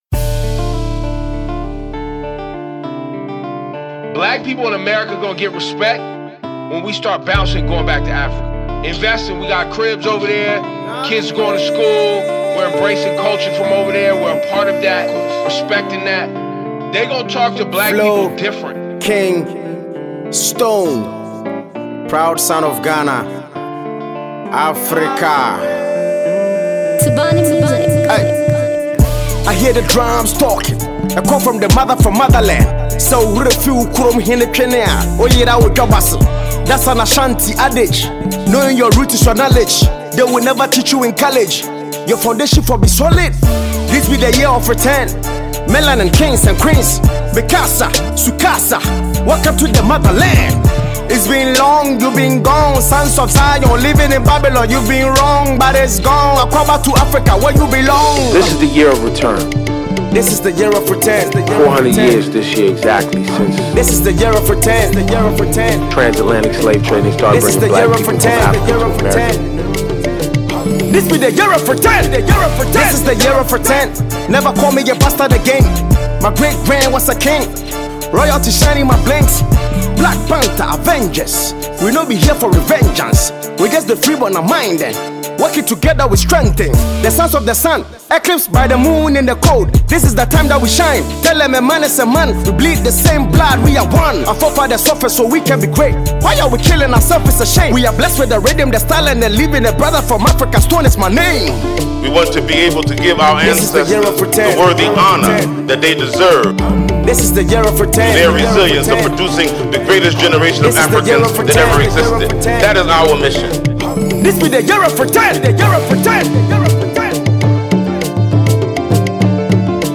Award winning rapper